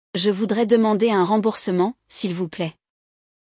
Durante a aula de áudio, você escutará um diálogo entre um cliente e um atendente, absorvendo vocabulário útil e construções essenciais para se comunicar com clareza.